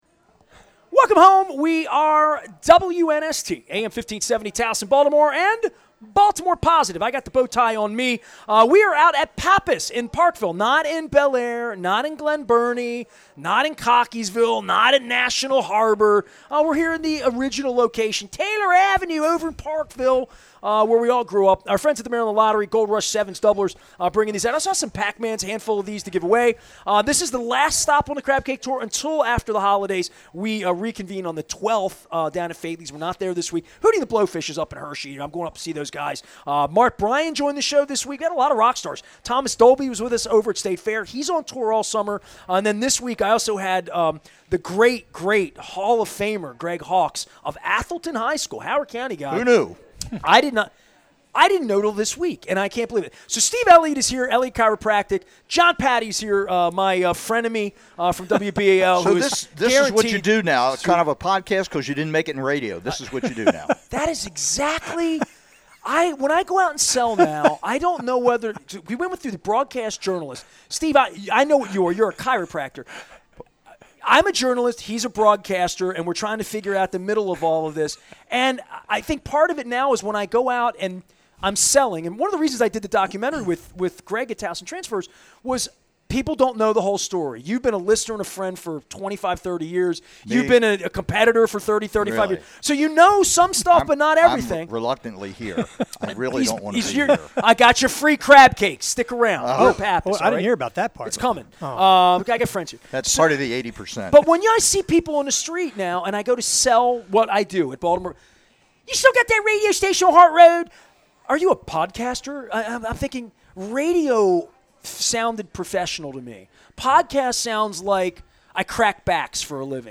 at Pappas in Parkville